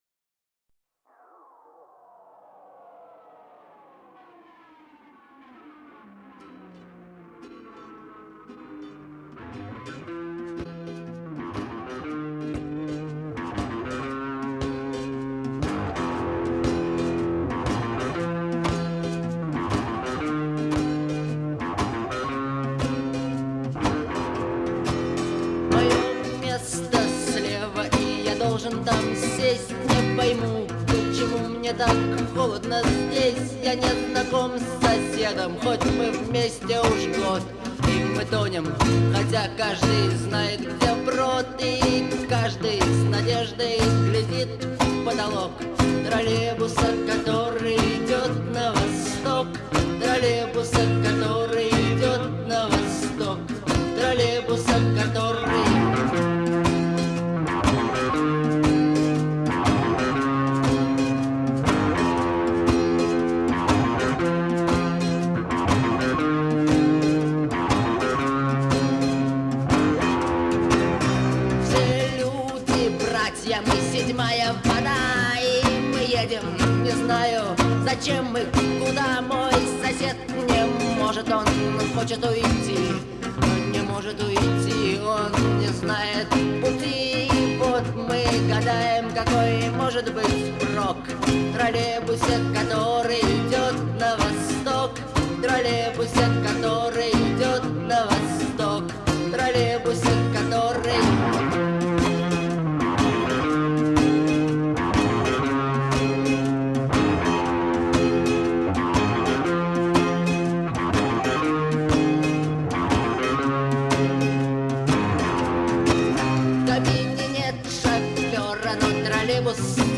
наполненный меланхолией и ностальгией.